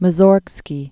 (mə-zôrgskē, -sôrg-, msərg-), Modest Petrovich 1839-1881.